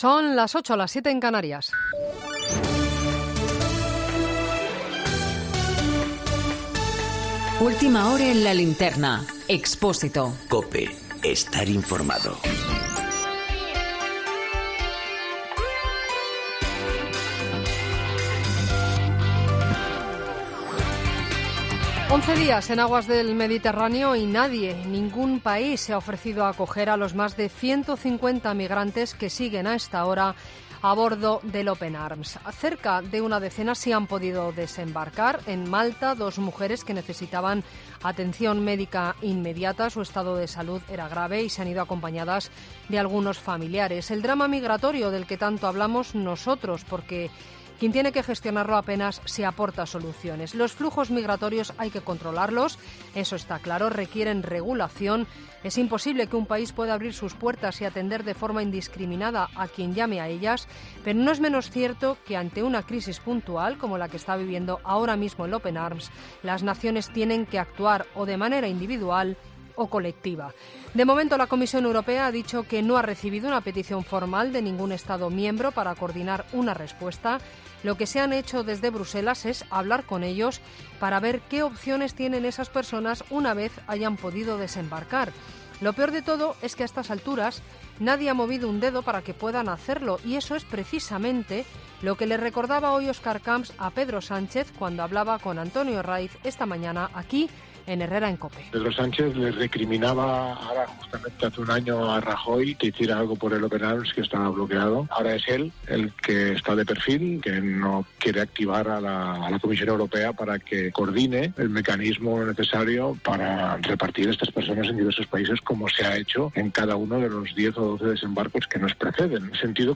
Boletín de noticias de COPE del 12 de agosto de 2019 a las 20.00 horas